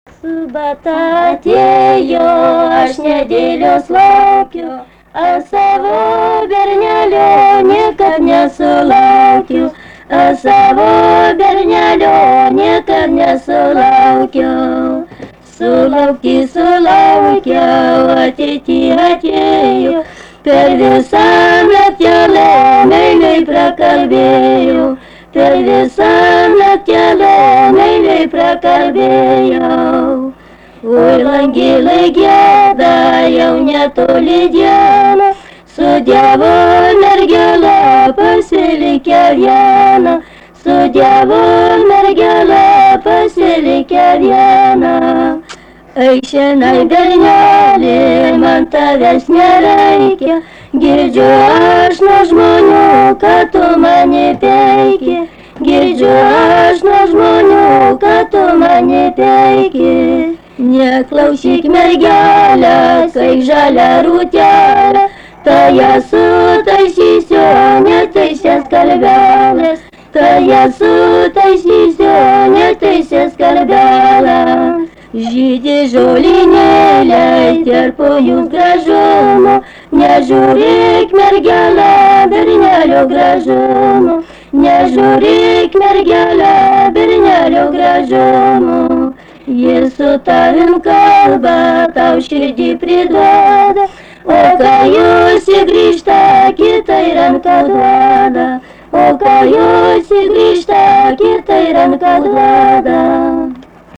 Dalykas, tema daina
Erdvinė aprėptis Kriokšlys
Atlikimo pubūdis vokalinis
2 balsai